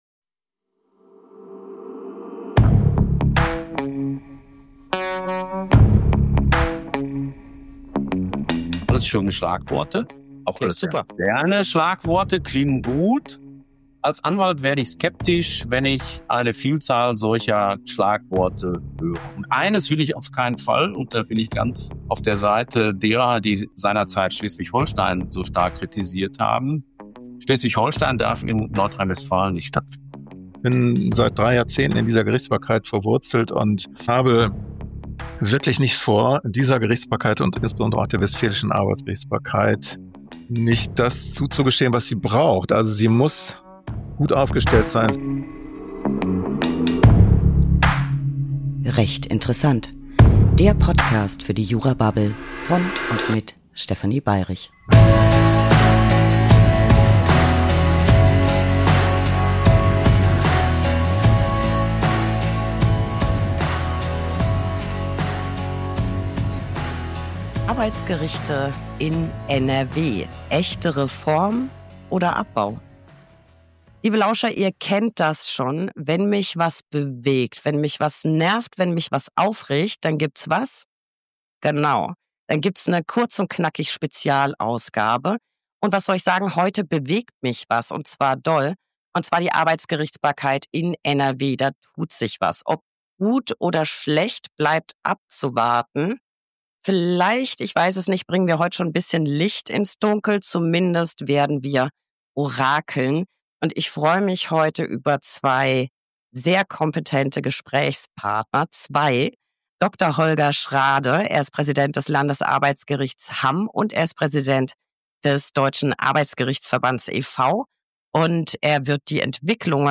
… continue reading 181 에피소드 # Gesellschaft # Politik # Bildung # Nachrichten # Bundesrechtsanwaltskammer # Anwalt # Rechtsanwalt # Recht # Talk # Interview # Gerichte # Rechtsstaat # Brak # Anwaltschaft